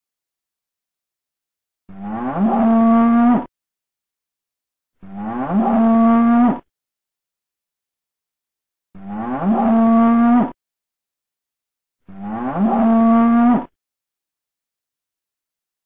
Звуки коровы
Корова тихо мычит